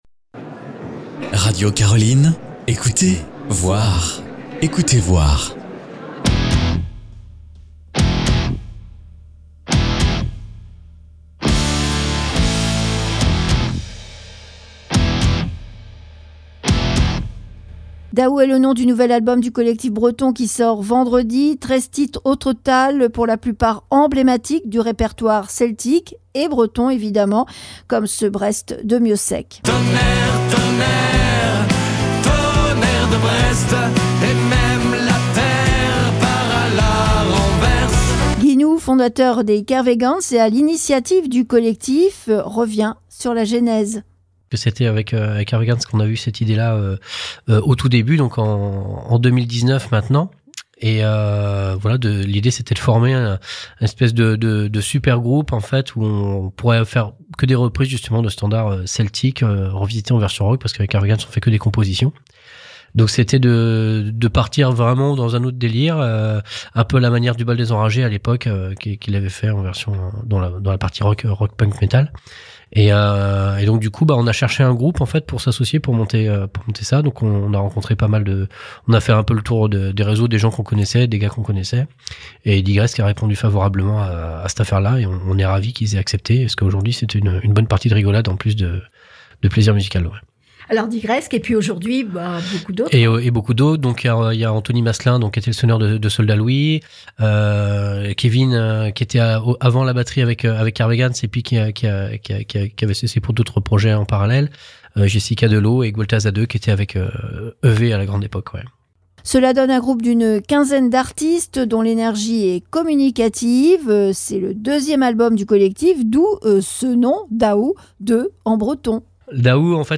dans les studios de Radio Caroline